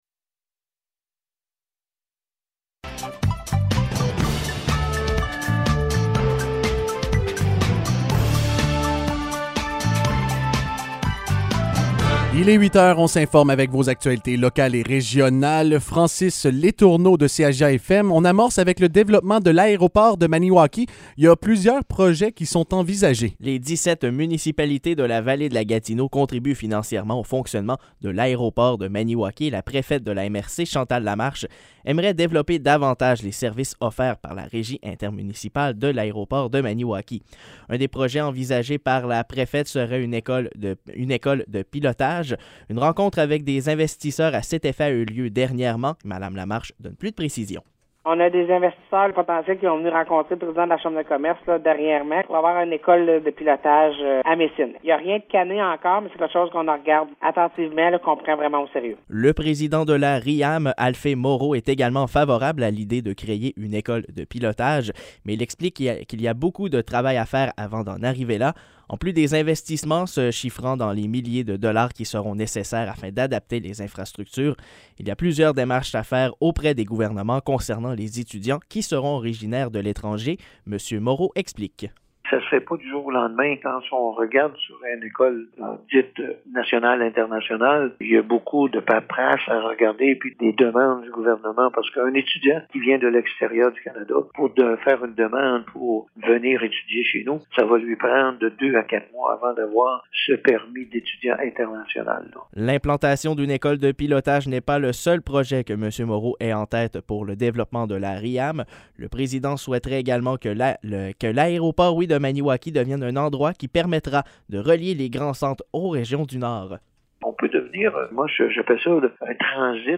Nouvelles locales - 24 février 2022 - 8 h